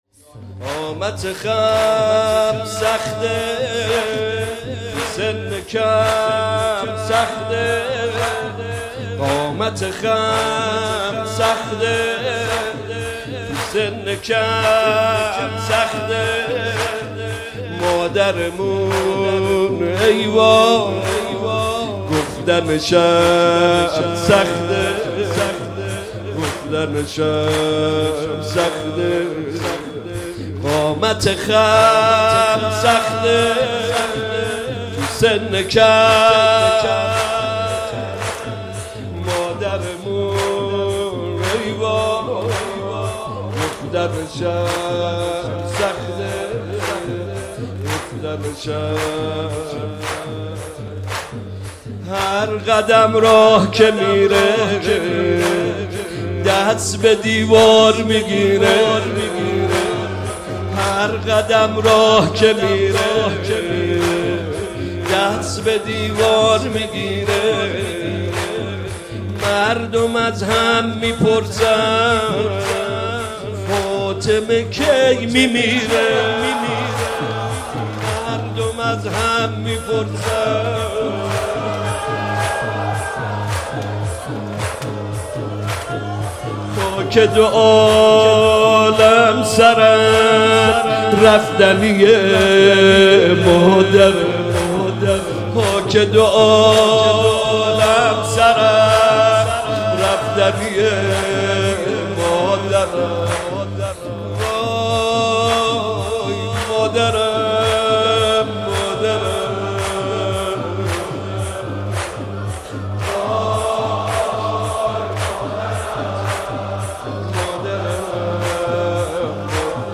شب شهادت حضرت زهرا - زمینه - قامت خم سخته